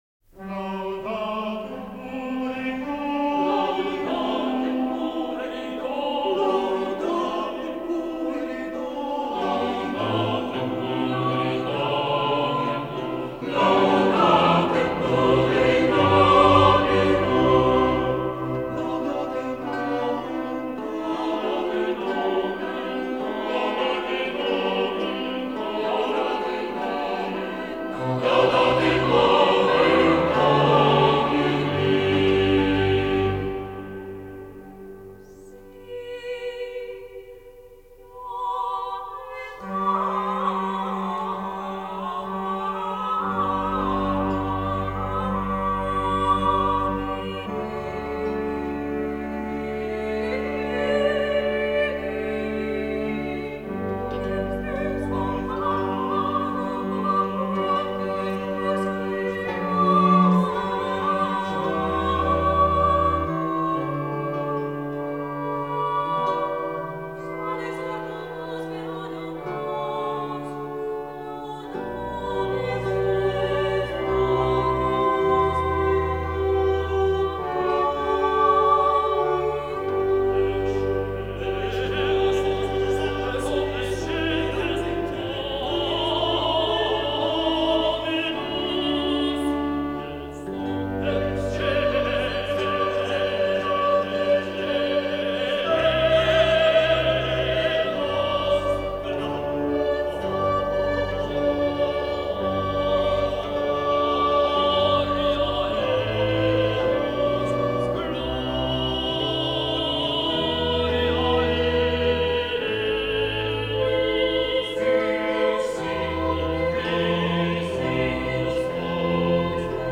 Garīgā vokālā mūzika